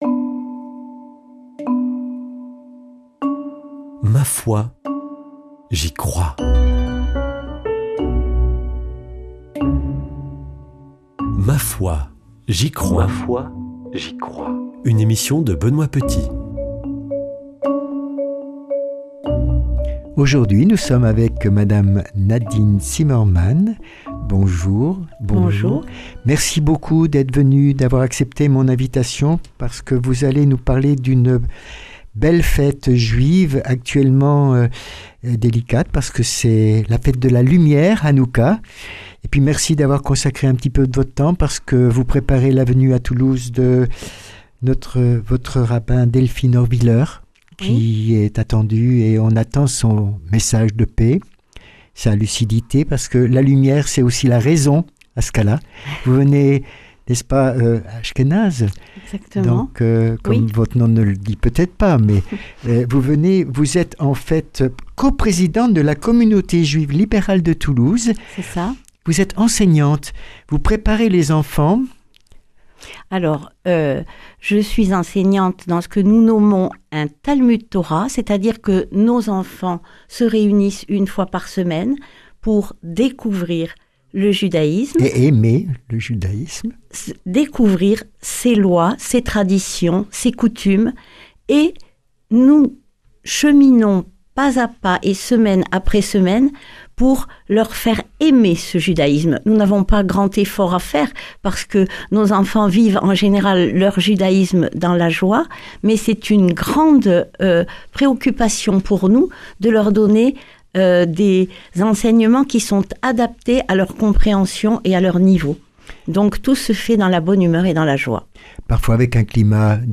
[ Rediffusion ]